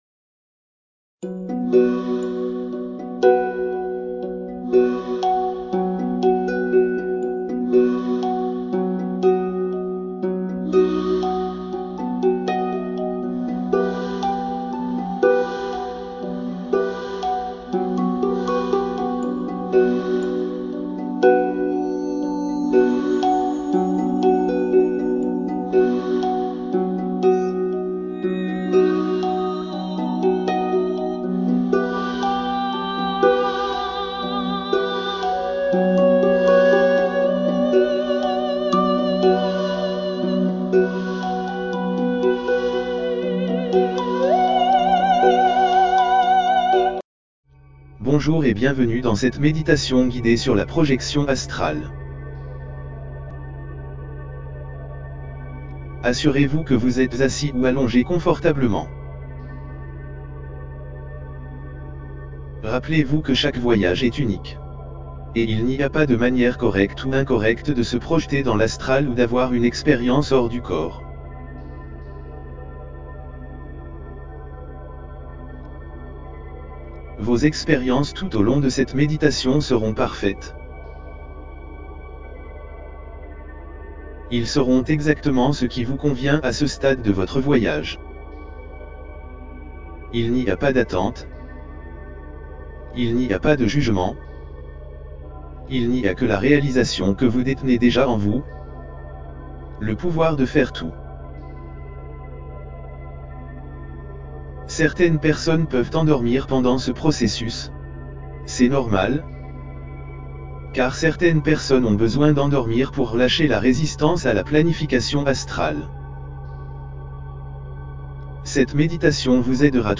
OBEOutOfBodySpokenVisualizationMeditationExperienceFR.mp3